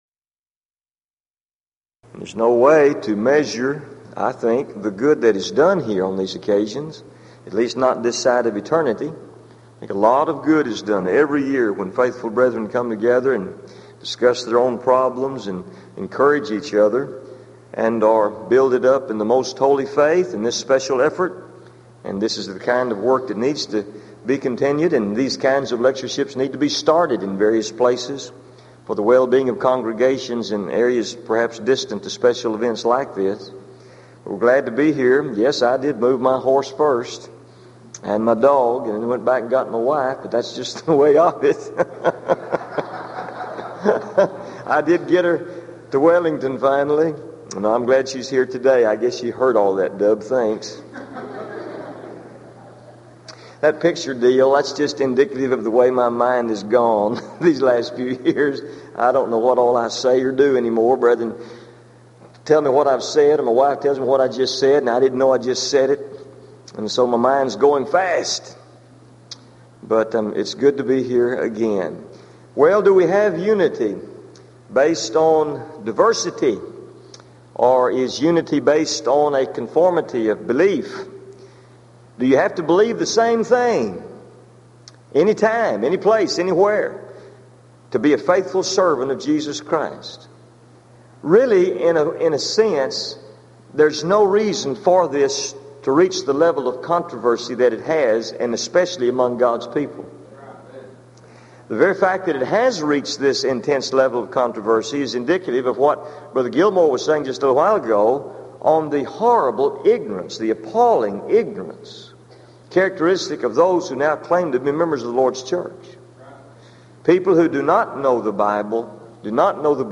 Title: DISCUSSION FORUM: Should We Seek Unity In Diversity Or Conformity?
Event: 1993 Denton Lectures